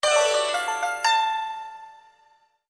ExitReached.wav